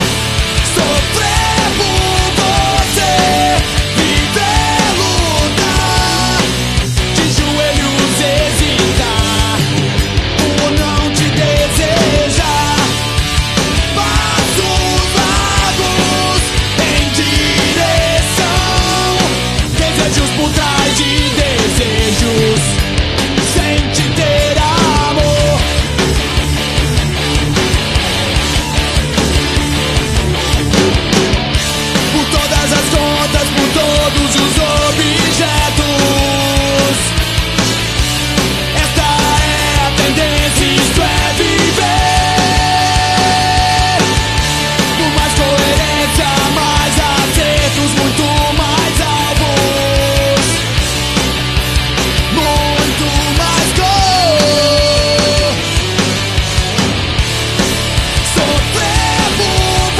hardcore hc hard core punk